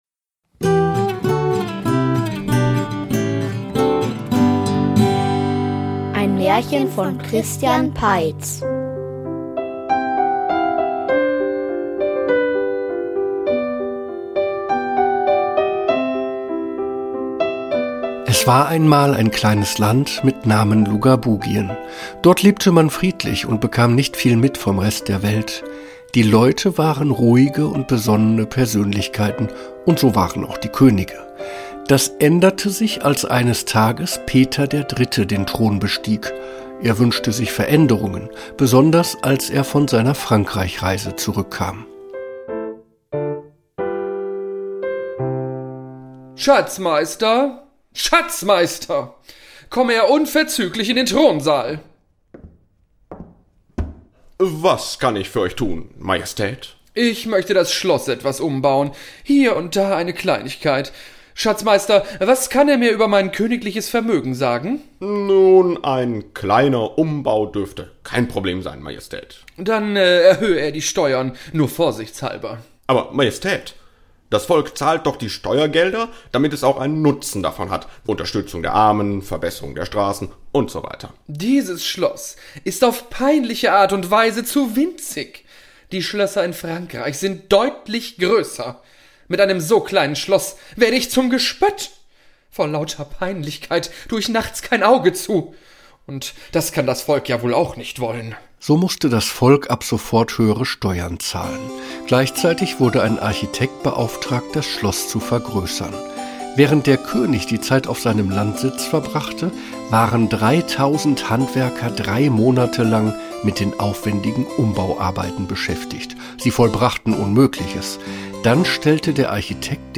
Ein Märchenhörspiel